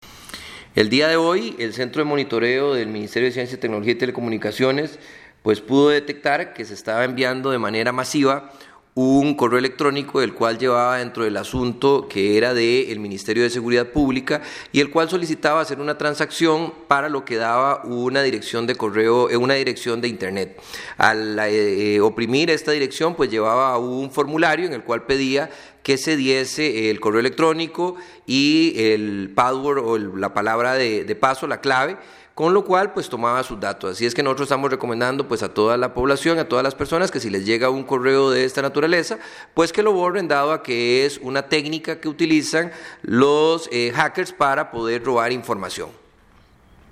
Audio del ministro Luis Adrián Salazar sobre alerta ante correo electrónico masivo fraudulento